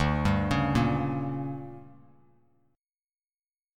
DM7sus4#5 chord